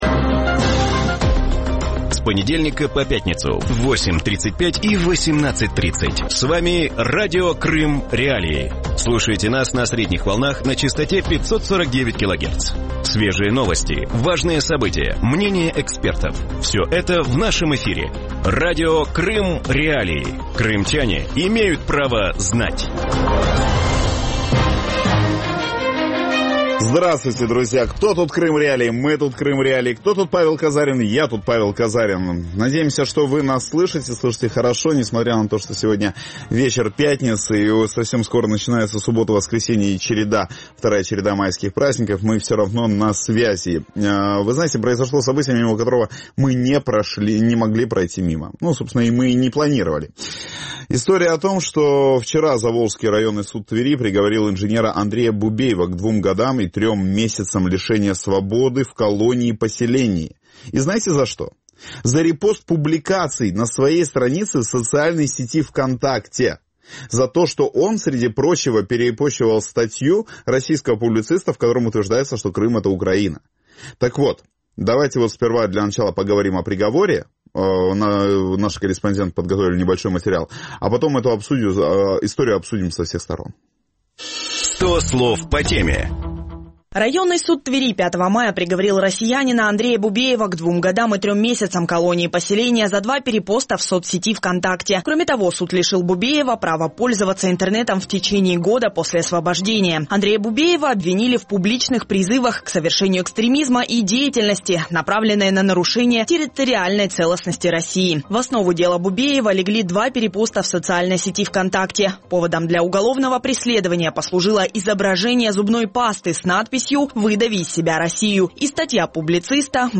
В вечернем эфире Радио Крым.Реалии обсуждают приговор